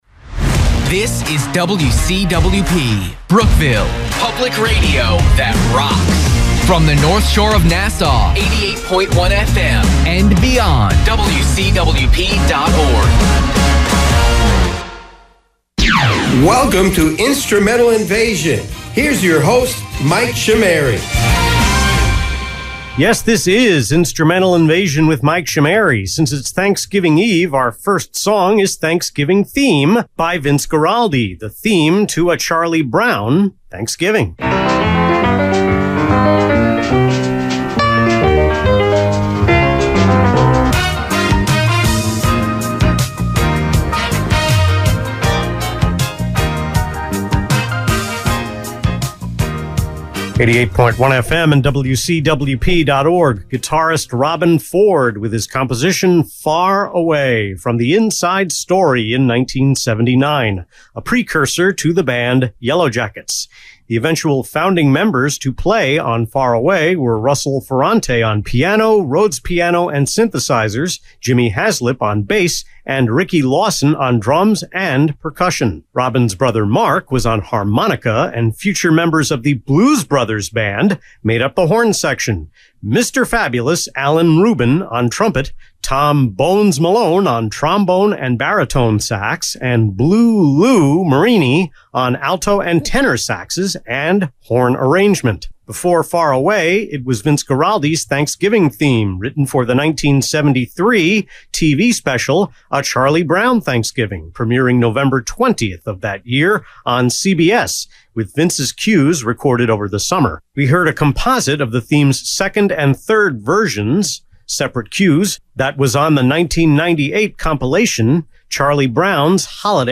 So, click here to download the scoped aircheck or listen below: